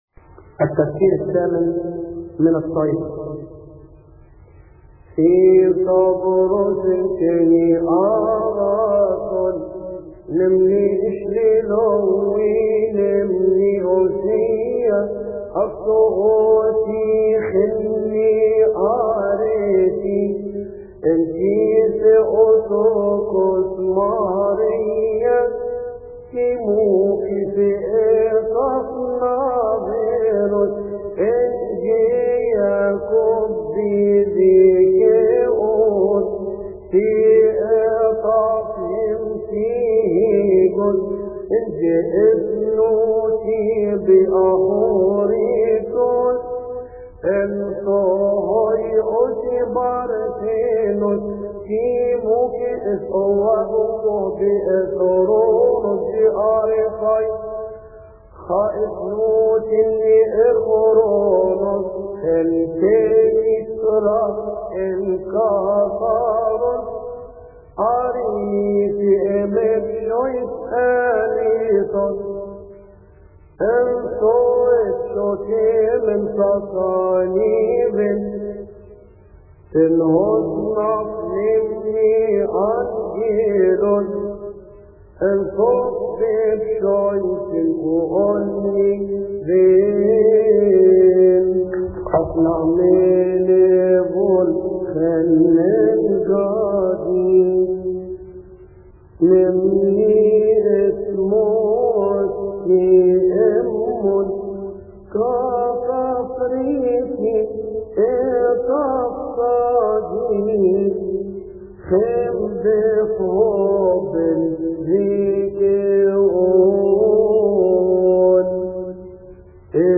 يصلي في تسبحة عشية أحاد شهر كيهك